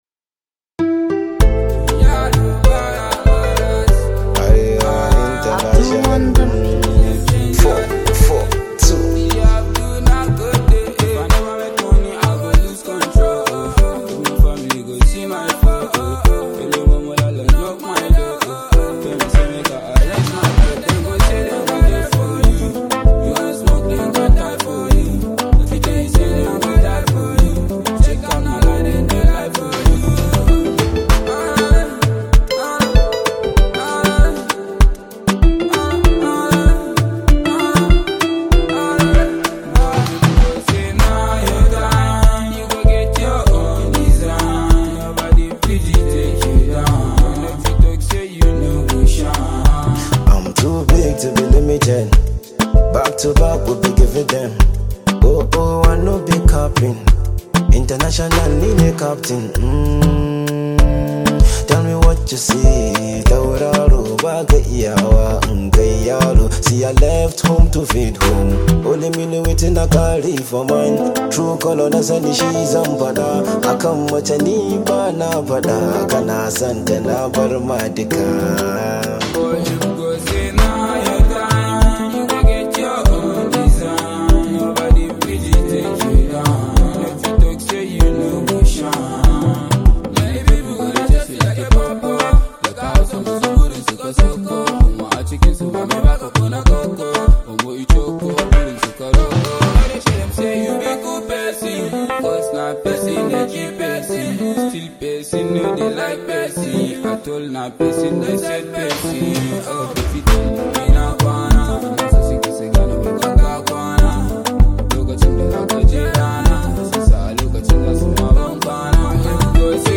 Hausa Hip Hop
an Arewa rooted song